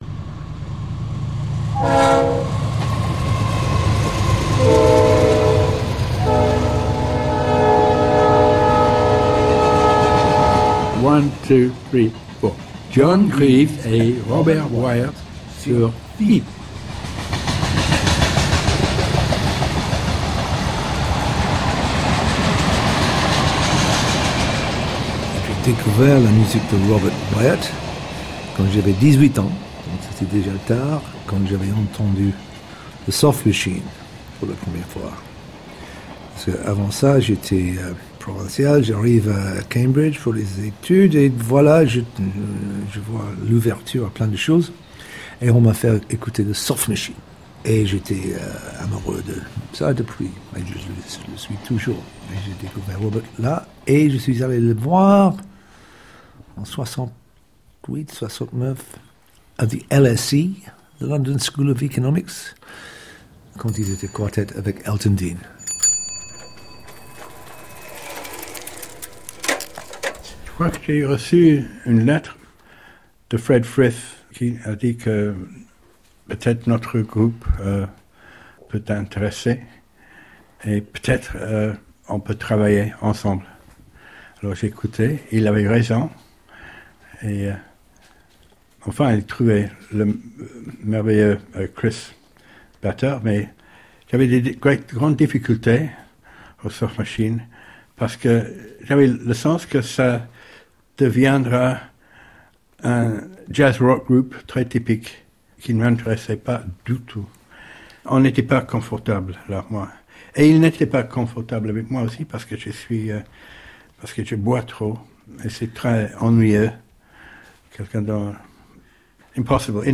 Live At FIP - Robert Wyatt's Interview by John Greaves - 2019
interview_RW.mp3